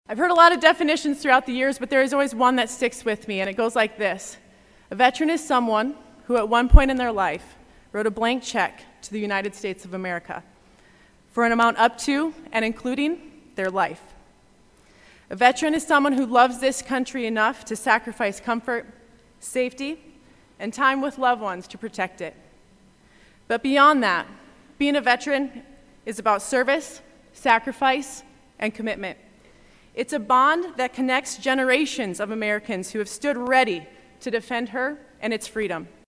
Aberdeen Central High School hosted Veterans Day ceremony
ABERDEEN, S.D.(HubCityRadio)- On Tuesday(Veterans Day), Aberdeen Central High School hosted their annual Veteran Day Ceremony honoring those who served in the military.